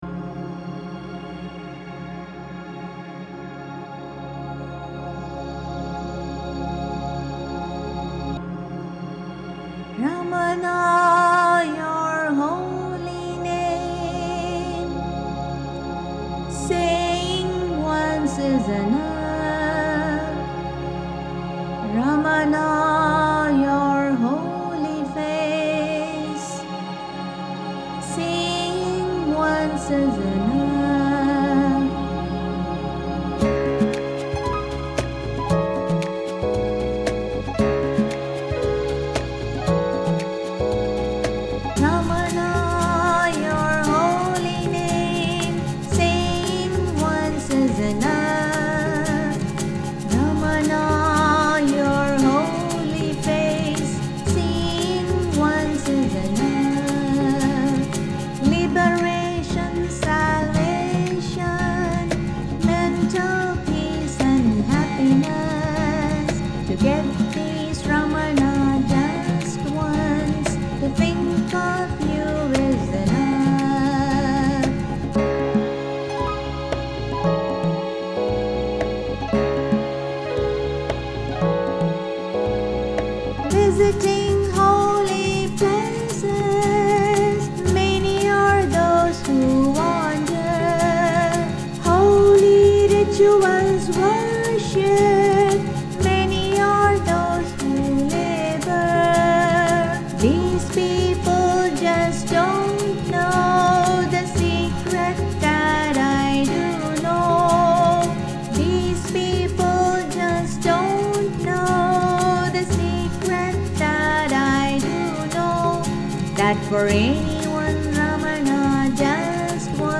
Ramana Maharshi Poetry & Music : In Tamil, English, Sanskrit
My Song Dedication in TAMIL